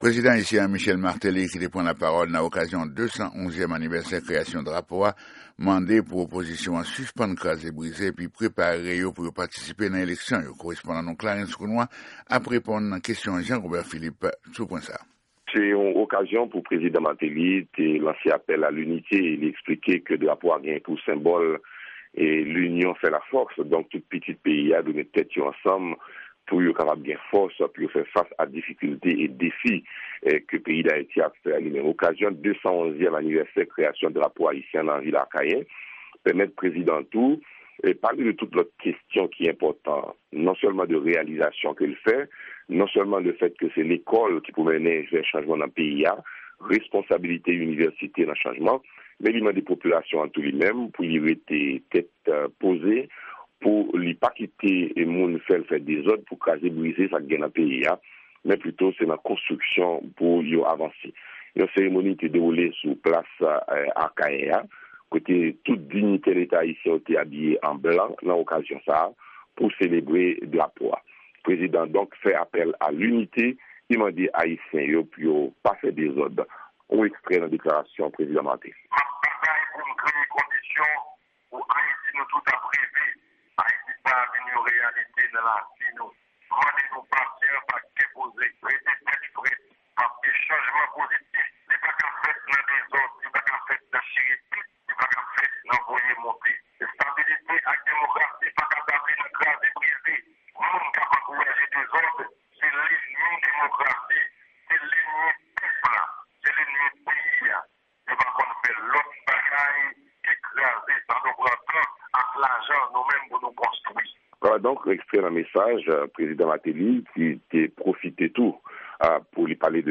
Repòtaj sou Fèt Drapo Ayisyen an ann Ayiti ak Dyaspora a 18 me 2014